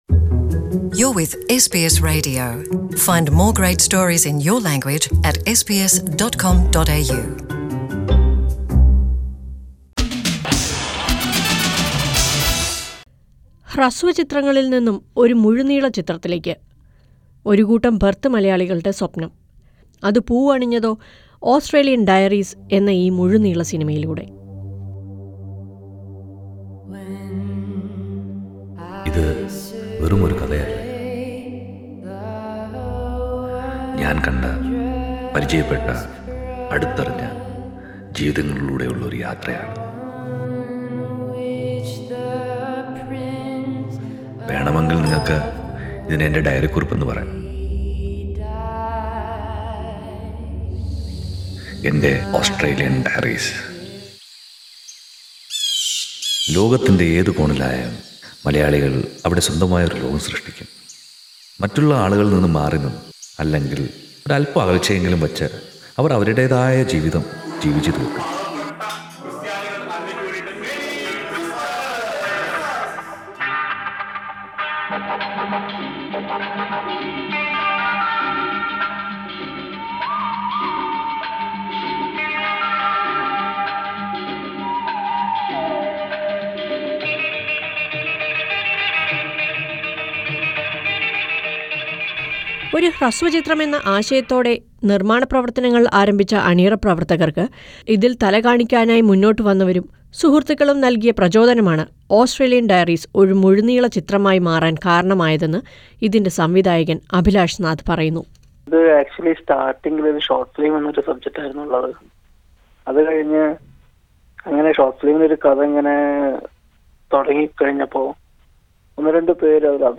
The Perth Malayalees have released a full length Malayalam movie names Australian Diaries. Listen to a report on that.